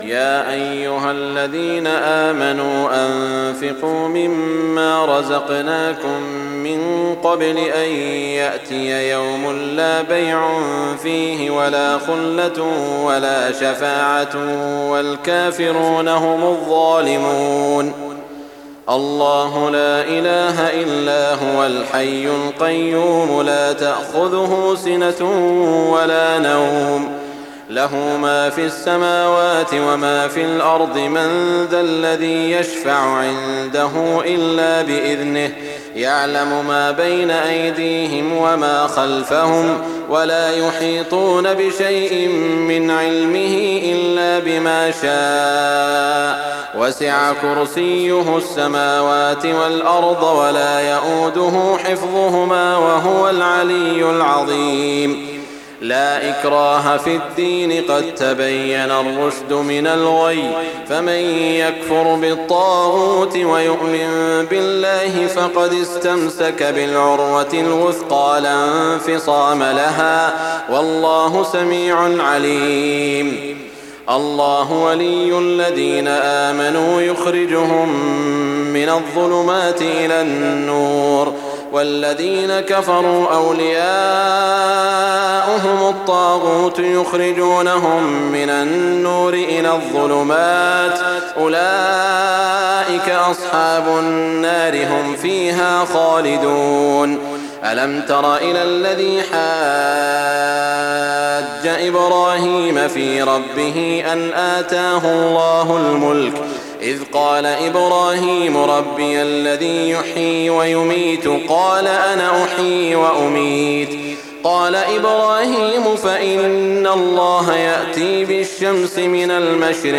تهجد ليلة 23 رمضان 1419هـ من سورتي البقرة (254-286) و آل عمران (1-32) Tahajjud 23 st night Ramadan 1419H from Surah Al-Baqara and Aal-i-Imraan > تراويح الحرم المكي عام 1419 🕋 > التراويح - تلاوات الحرمين